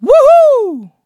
Kibera-Vox_Happy4_kr.wav